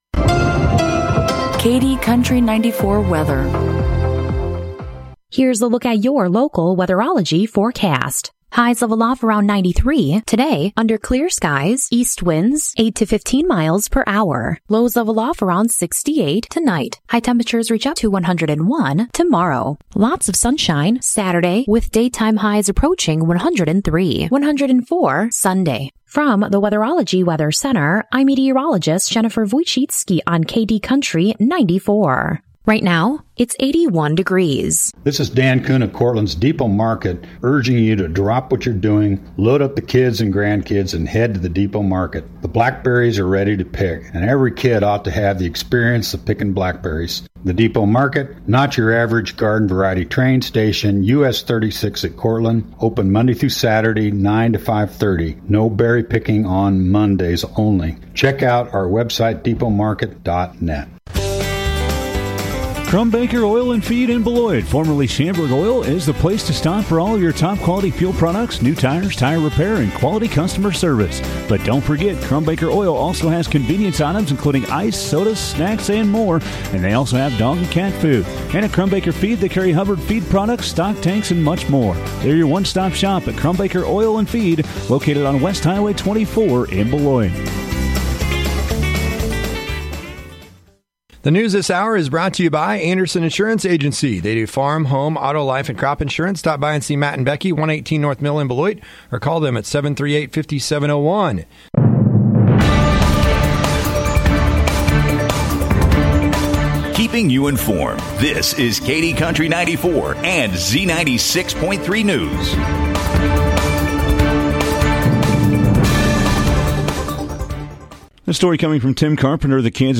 KDNS NEWS PODCAST